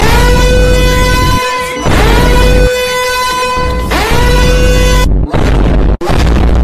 Loud Alarm Sound Effect Free Download
Loud Alarm